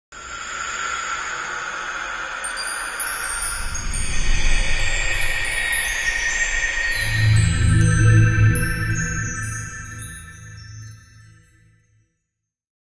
Slow Wind Magic is a free nature sound effect available for download in MP3 format.